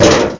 Amiga 8-bit Sampled Voice
klonk3.mp3